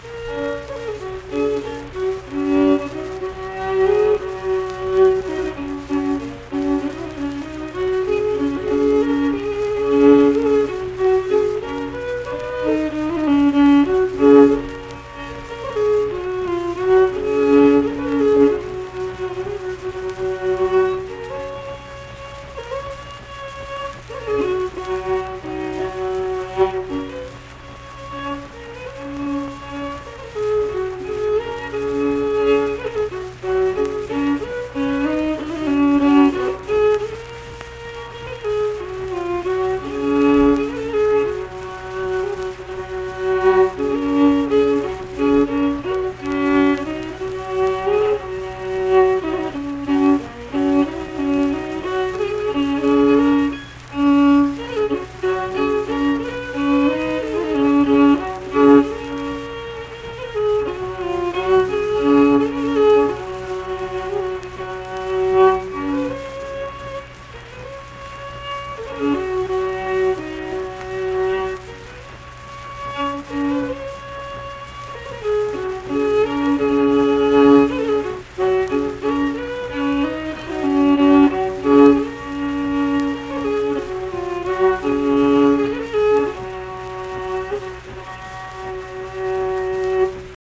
Unaccompanied fiddle music
Verse-refrain 2(1). Performed in Ziesing, Harrison County, WV.
Instrumental Music
Fiddle